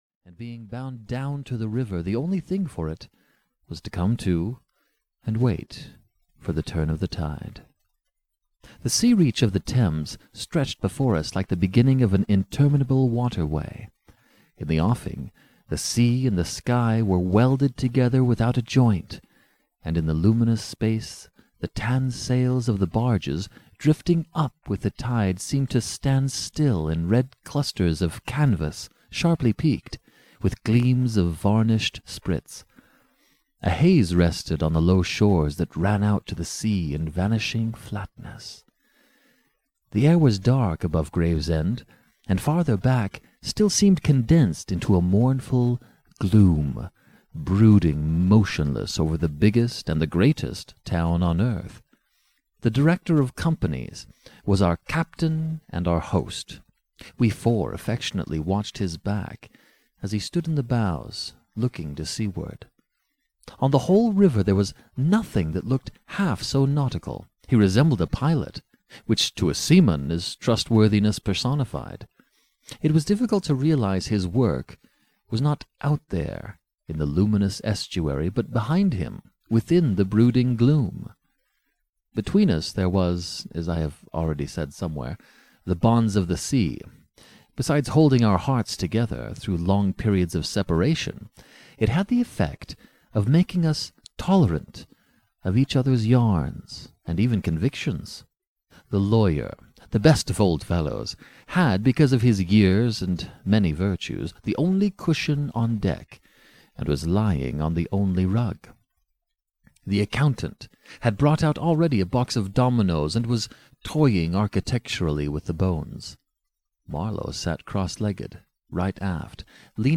Ukázka z knihy
He masterfully plays with a wide array of voices and accents and has since then produced over 500 audiobooks.